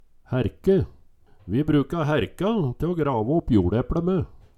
Høyr på uttala Image title